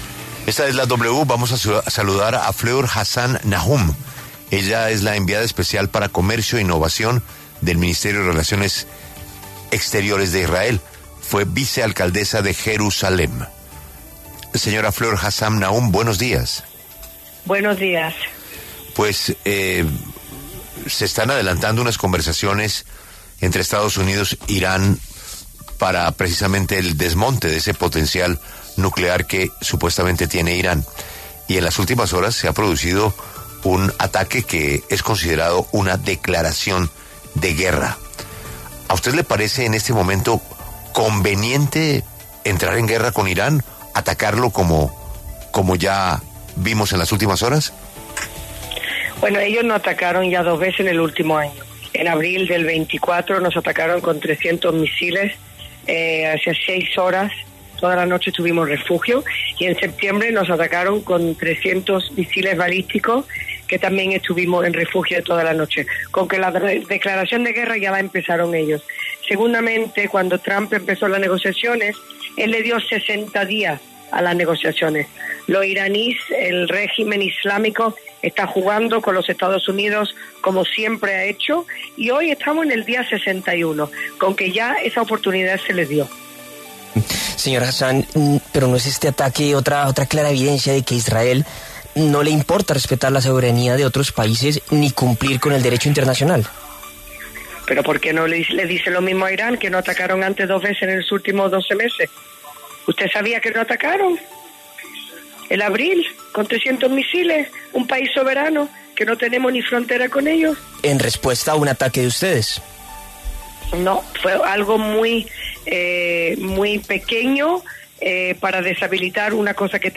Fleur Hassan-Nahoum, enviada especial para Comercio e Innovación del Ministerio de Exteriores de Israel, habló en La W sobre los ataques aéreos israelíes contra objetivos nucleares y militares en Irán.